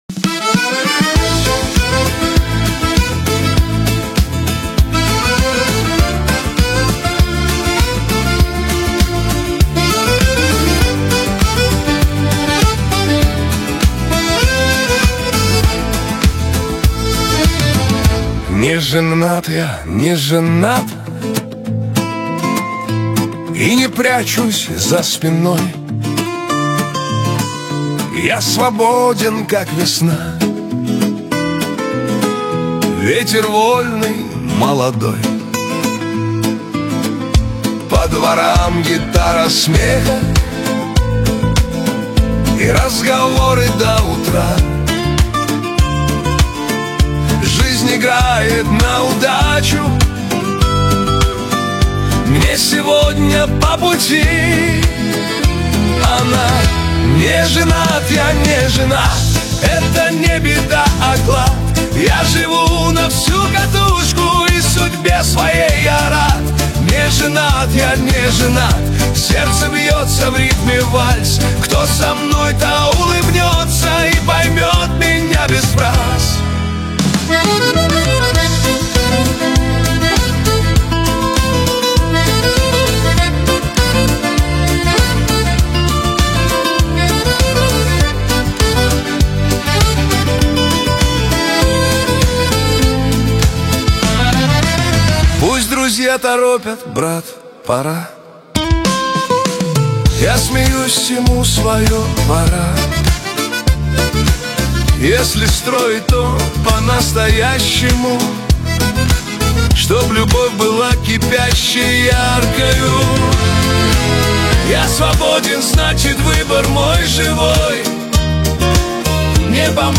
Новый шансон 2026